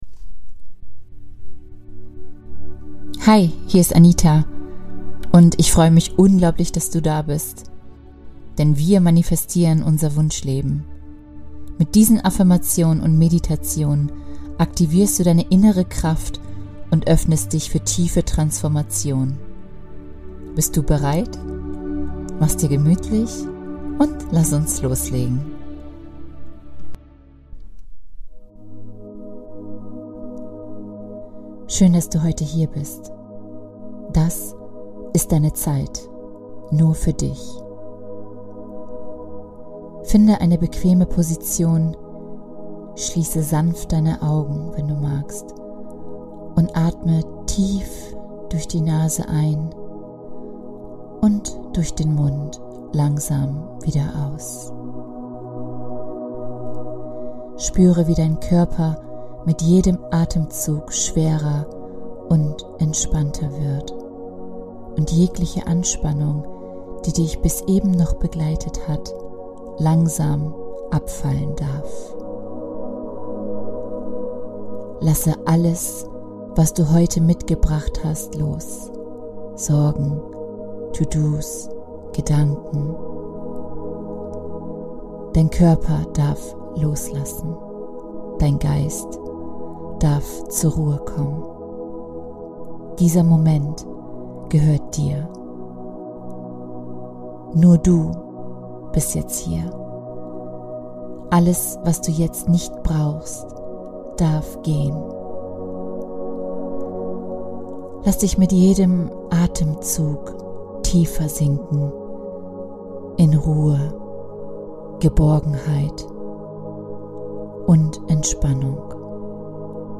Entdecke in dieser Episode, wie du dich selbst liebevoll annimmst, deine innere Stärke aktivierst und Dankbarkeit für dich und dein Leben spürst. Lass dich von Meditation, Affirmationen und sanften Visualisierungen begleiten – und nimm diese positive Energie und die Entspannung mit in deinen Alltag.